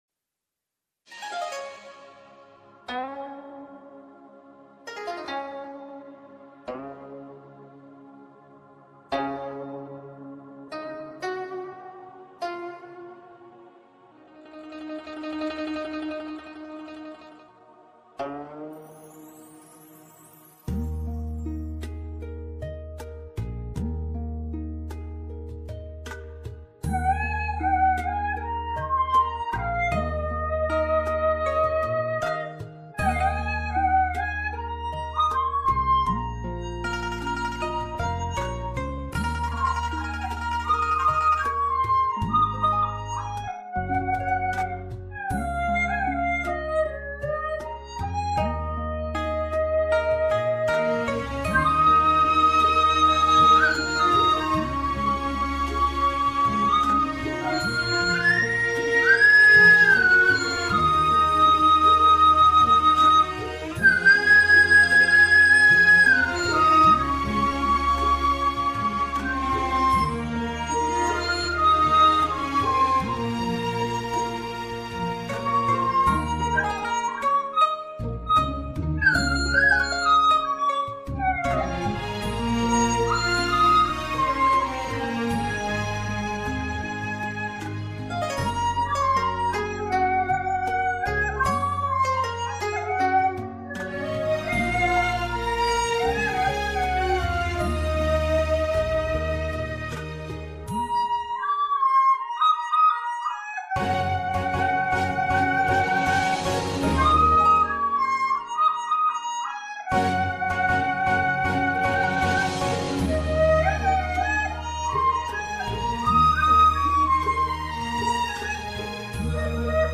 一般多见演奏乐曲中段慢板抒情部分，委婉优美，细腻感人。
好，清脆动人的笛声配上浓郁粗旷的西北风令人喜爱！
浓浓的西北味，很好听，特别有感染力。笛乐特别适合表现秦腔